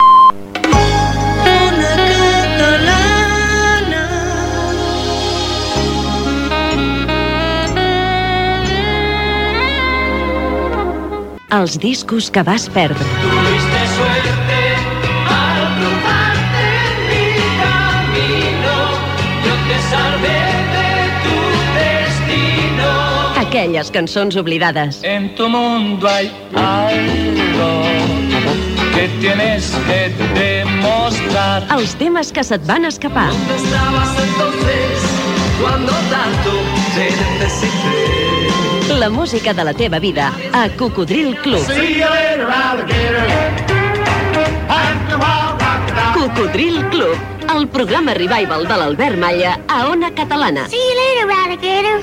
Indicatiu emissora i careta del programa
Musical